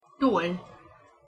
Pronunciation Hu Toll (audio/mpeg)